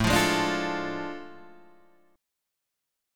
A Major 7th Suspended 4th Sharp 5th
AM7sus4#5 chord {5 8 6 7 6 5} chord